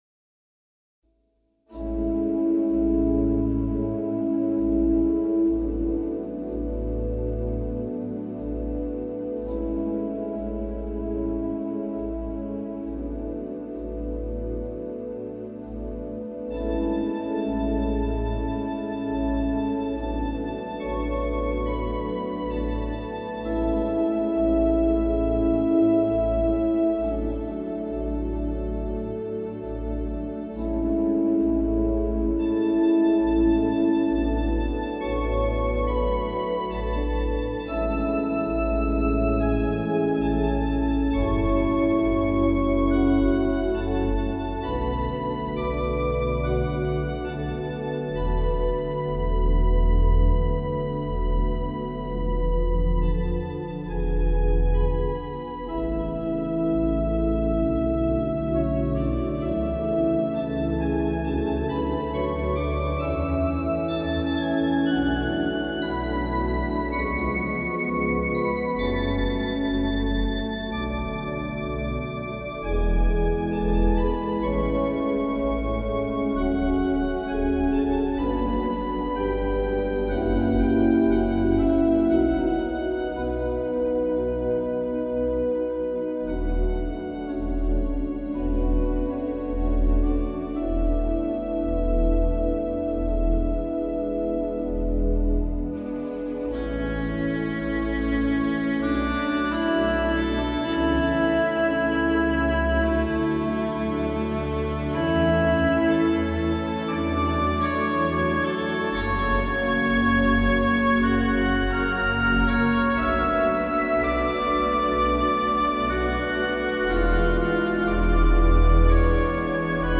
Peaceful works for quiet reflection